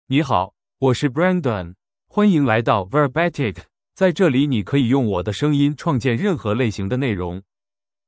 BrandonMale Chinese AI voice
Brandon is a male AI voice for Chinese (Mandarin, Simplified).
Voice sample
Male
Brandon delivers clear pronunciation with authentic Mandarin, Simplified Chinese intonation, making your content sound professionally produced.